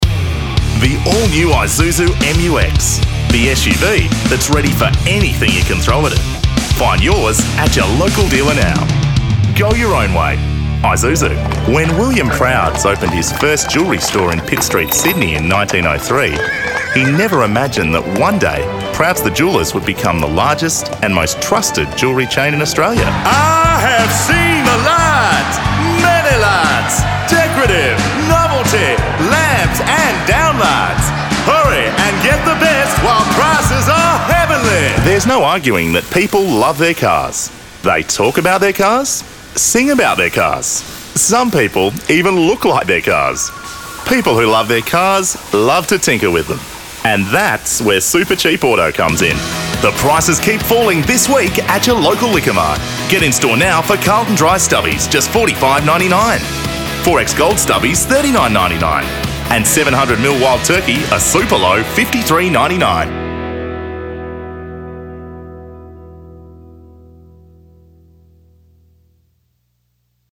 Male
English (Australian)
Adult (30-50)
Warm, confident Australian baritone with 20+ years as a radio announcer. Polished, conversational delivery that builds trust without the hard sell.
Main Demo